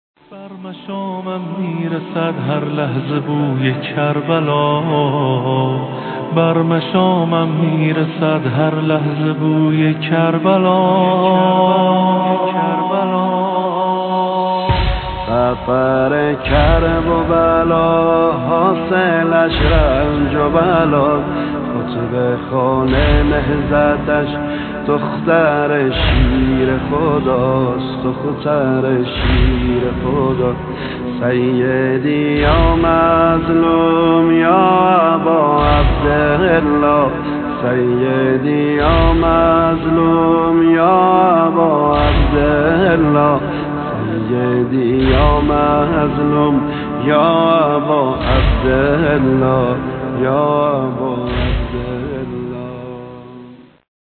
کد پیشواز مداحی